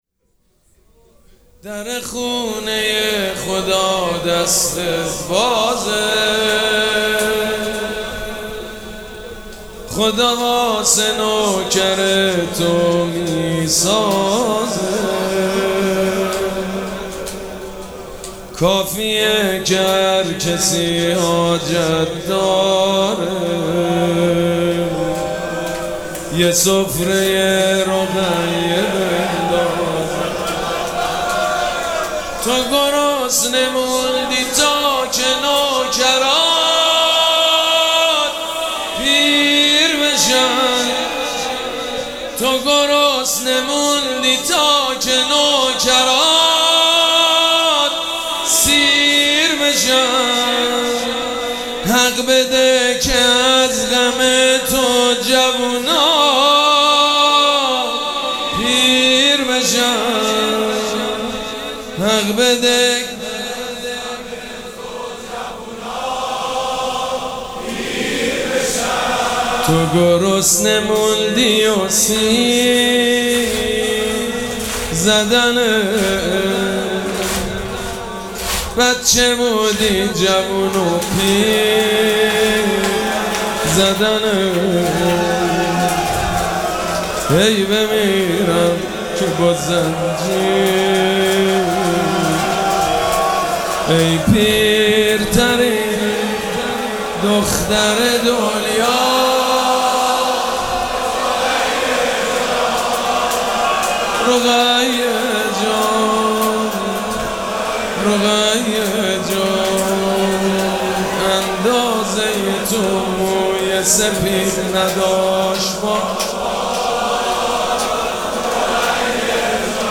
مراسم عزاداری شب سوم محرم الحرام ۱۴۴۷
زمزمه
حاج سید مجید بنی فاطمه